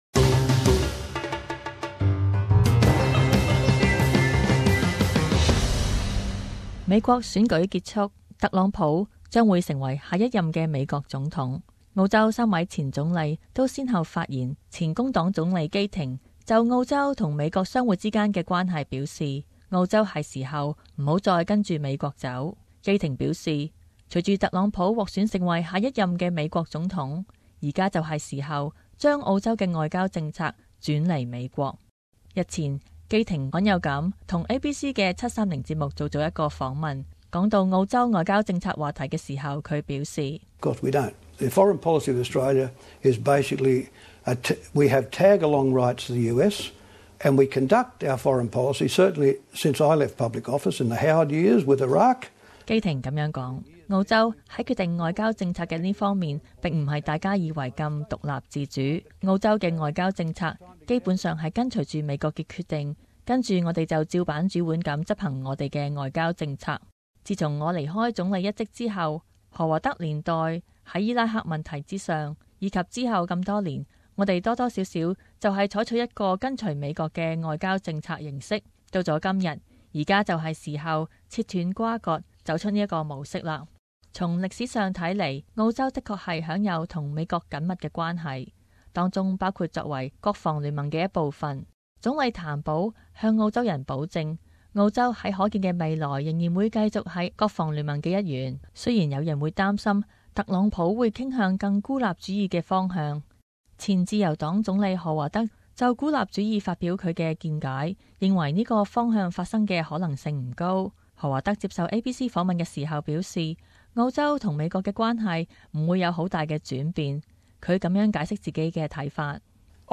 【時事報導】 前總理看澳美關係